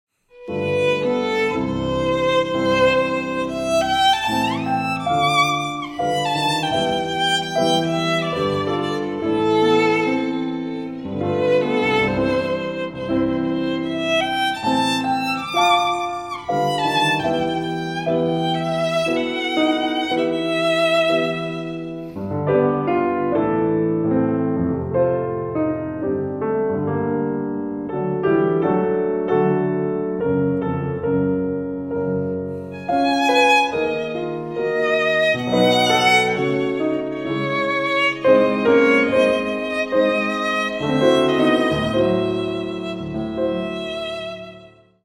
in 1917 in a late romantic style